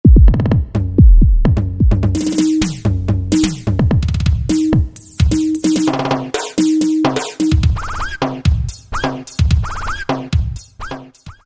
64-voice expandable synthesizer
103. arp:ElectroGroov Very, very cool electronic groove
103 arp_ElectroGroov.mp3